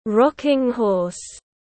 Ngựa gỗ bập bênh tiếng anh gọi là rocking horse, phiên âm tiếng anh đọc là /ˈrɒk.ɪŋ ˌhɔːs/